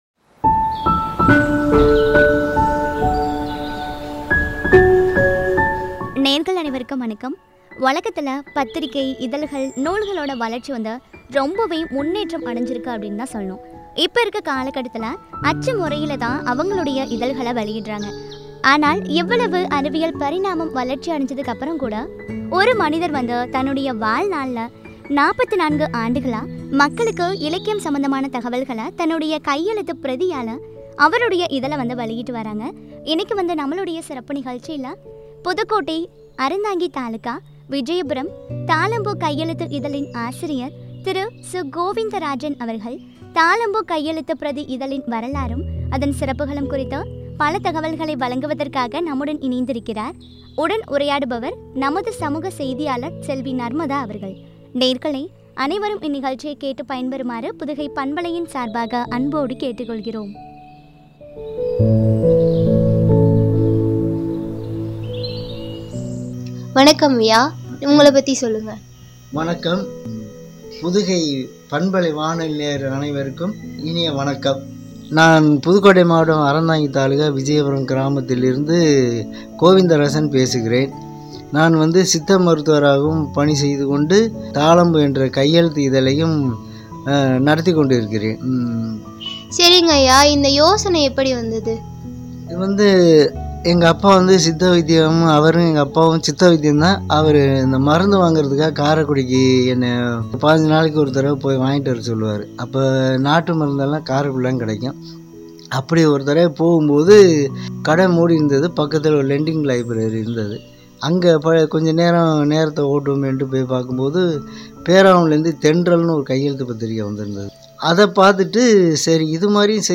சிறப்பும் பற்றிய உரையாடல்.